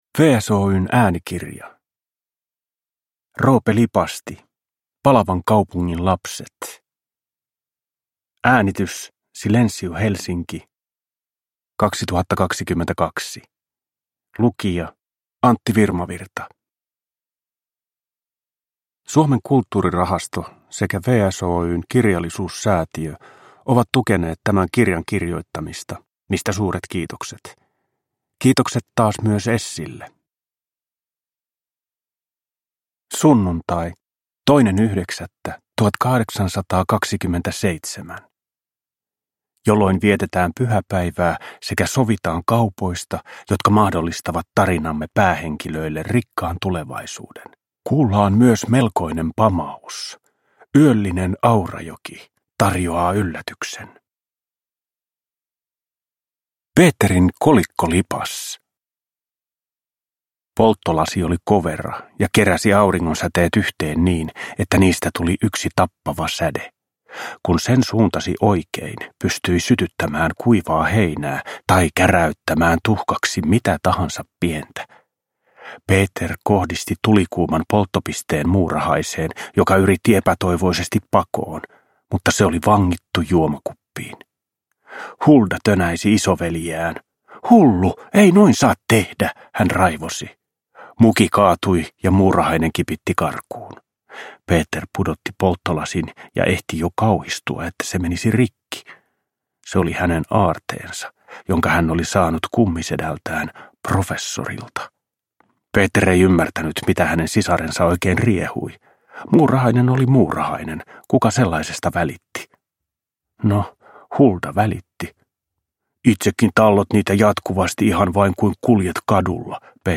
Palavan kaupungin lapset – Ljudbok
Uppläsare: Antti Virmavirta